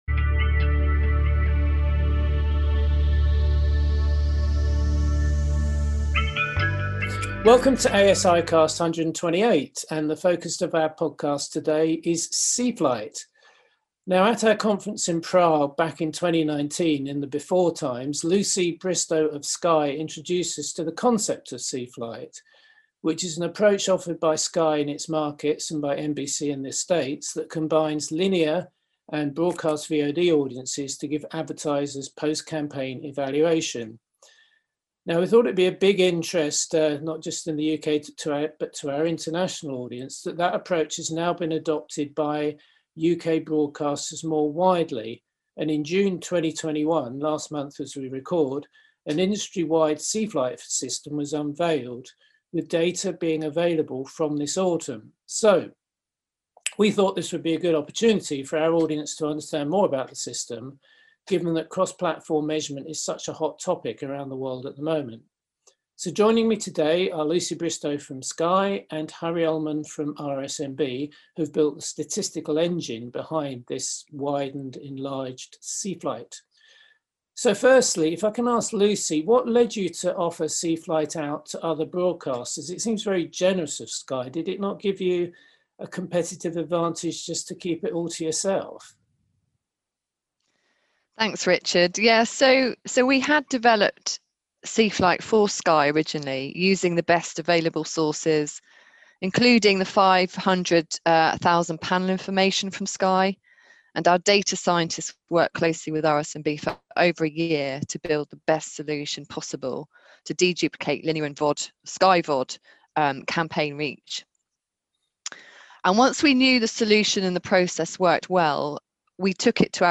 asicast-128-Cflight-audio-mono.mp3